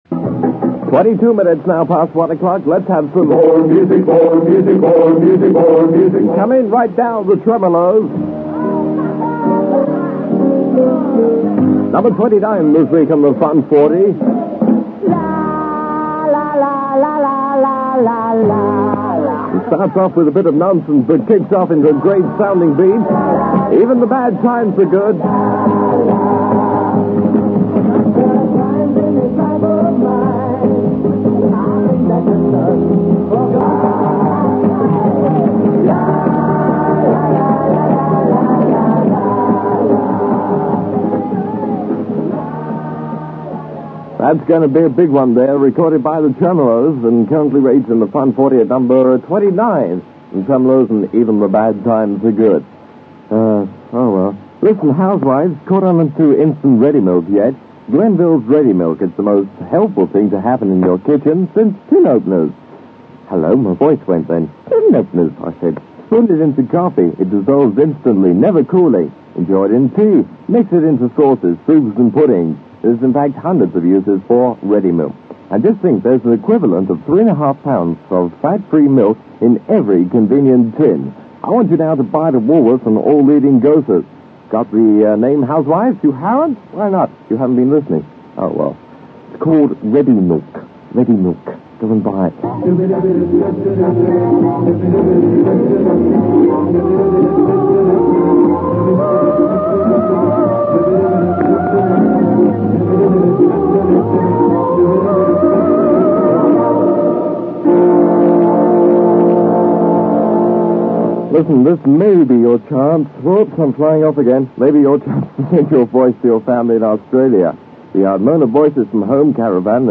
However, during the planning of the station it was decided to switch to a Top 40 format, with most programmes presented live from the ship. The station’s intention to aim for a local audience was reflected in the huge amount of commercials from small local businesses - many of which can be heard in these programme extracts:-